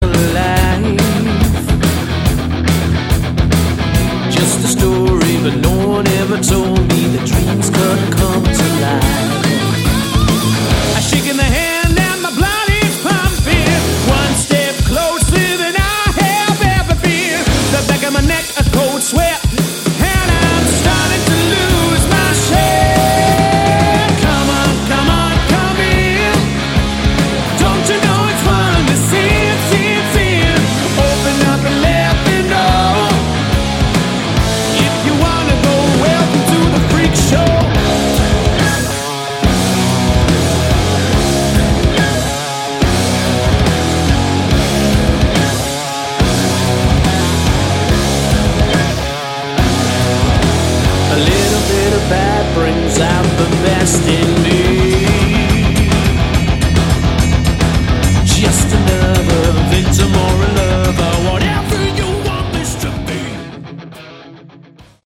Category: Hard Rock
lead vocals
guitar
bass
drums
More like modern hard rock.